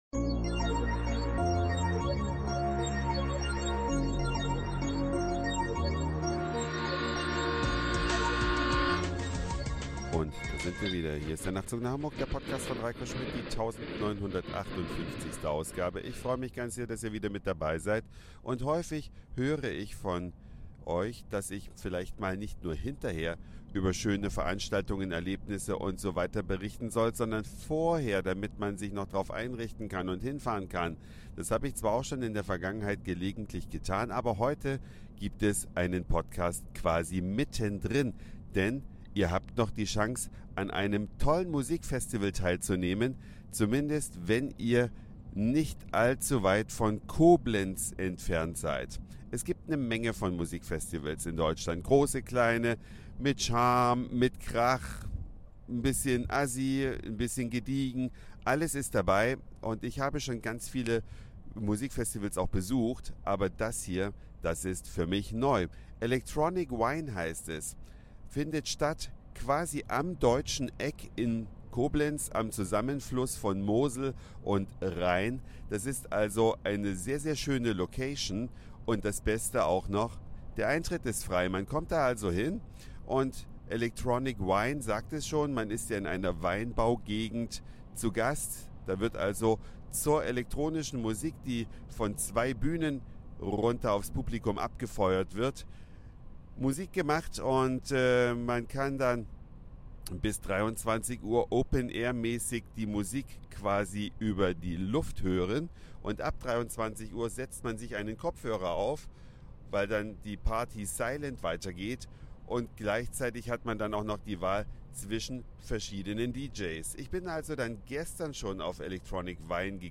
Eine Reise durch die Vielfalt aus Satire, Informationen, Soundseeing und Audioblog.
Ein schönes Musik Festival am Deutschen Eck in Koblenz Besser als